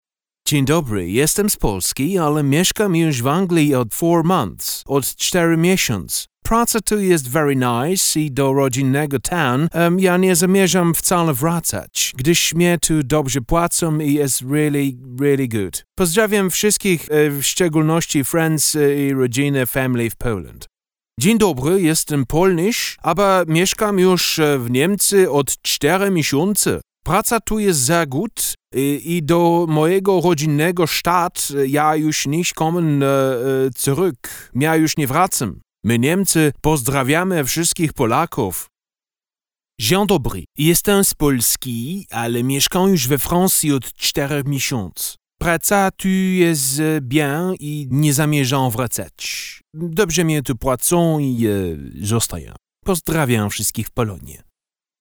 Sprechprobe: Sonstiges (Muttersprache):
Warm voice for all possible production types.